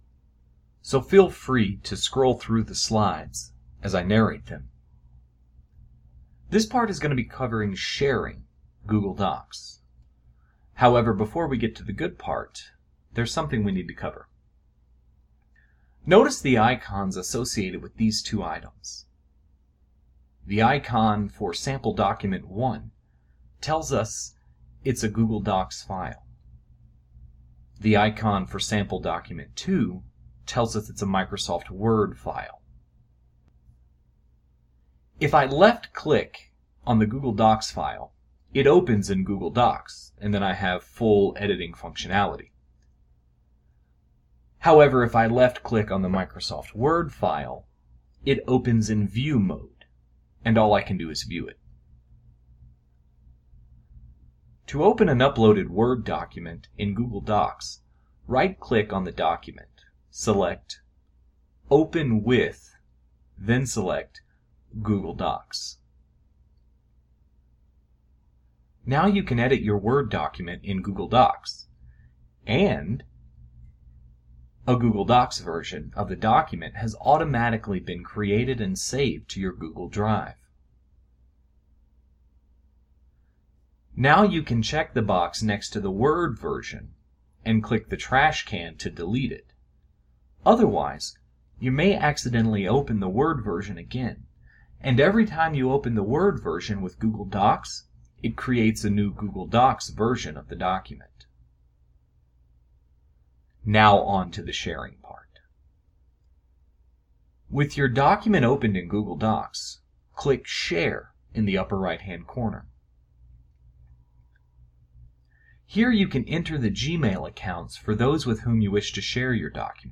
Scroll through the slides as I narrate them.